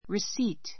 recei p t A2 risíːt リ スィ ー ト 名詞 領収書, レシート, 受け取り May I have a receipt, please?